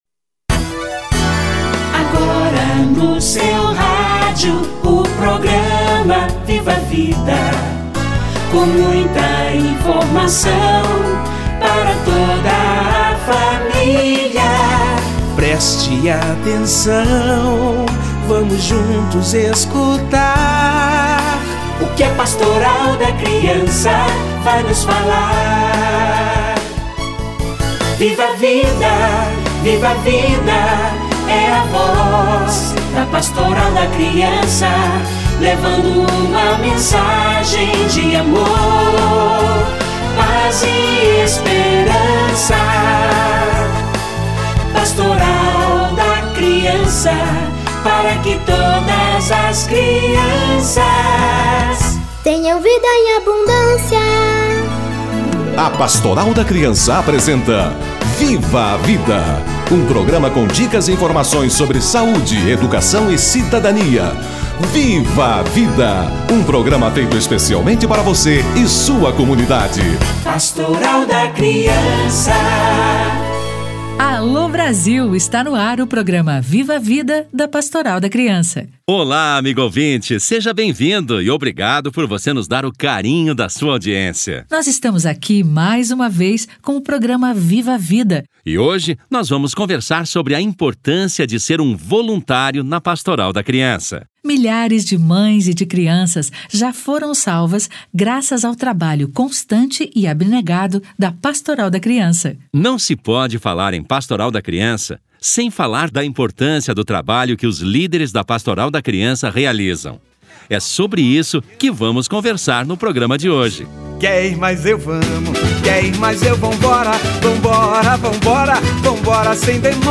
Para falar sobre esse assunto tão importante, ouvimos alguns Padres Assessores e alguns Coordenadores Diocesanos da Pastoral da Criança.